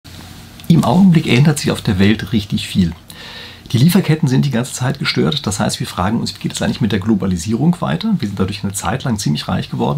Ausschnitte eines Interviews